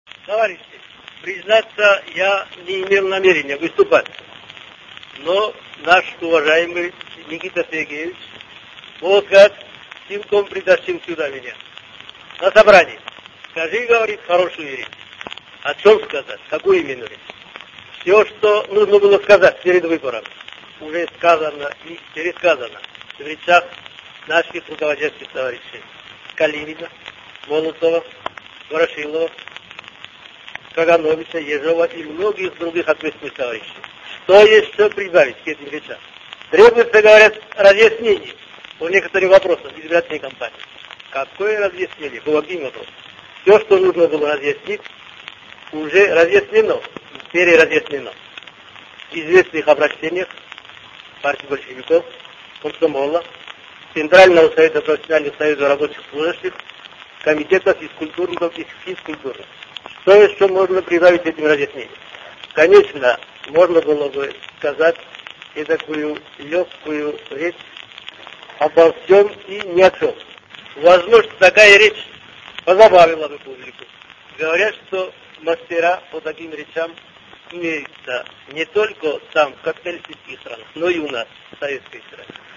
Józef Stalin (PAP) Mowa do wyborców wygłoszona przez Stalina 11 grudnia 1937 r. w Teatrze Wielkim w Moskwie.